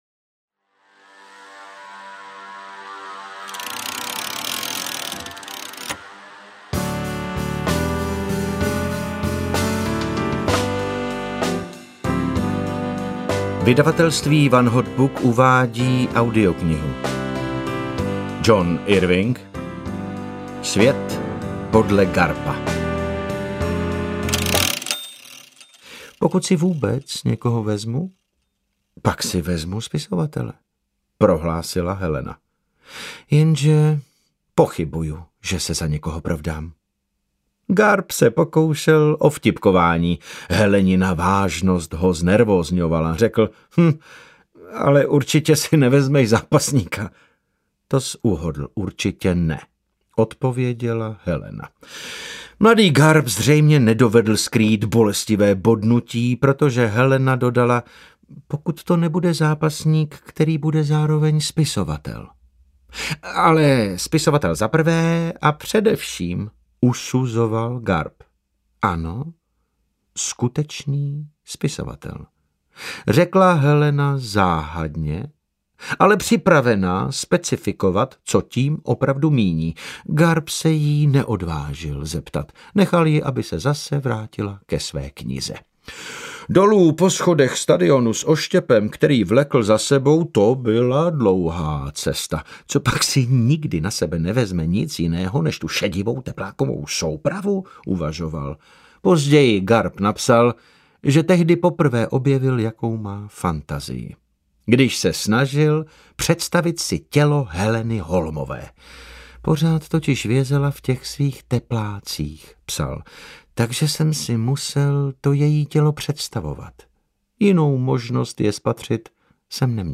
Svět podle Garpa audiokniha
Ukázka z knihy
• InterpretDavid Novotný